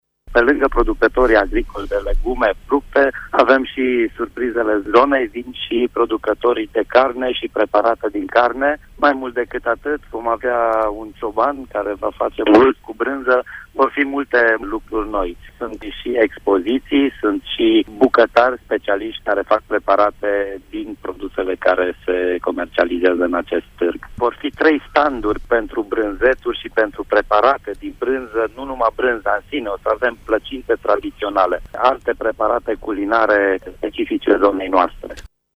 Vizitatorii sunt așteptați nu doar cu legume și fructe, ci și cu preparate tradiționale dintre cele mai variate, spune administratorul municipiului Brașov, Miklos Gantz: